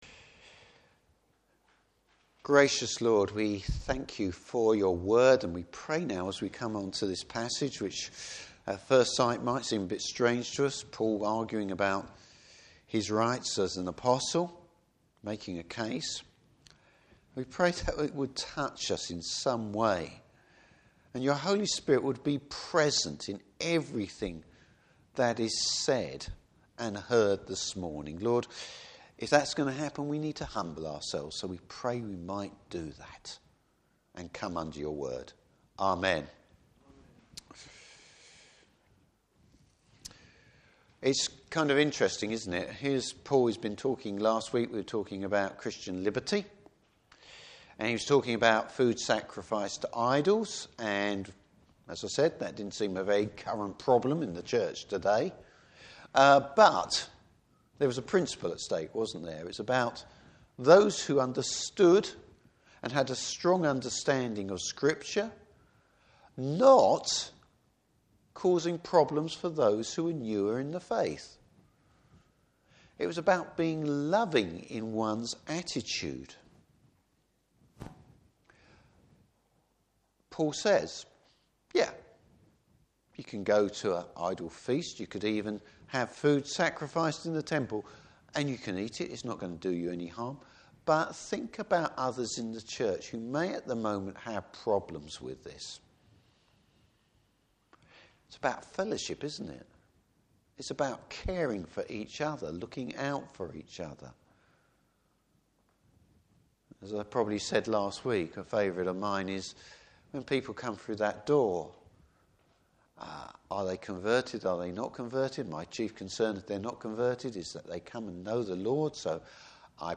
Service Type: Morning Service Apostle’s have rights, but Paul is willing to forgo them.